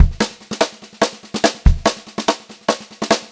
144SPBEAT1-L.wav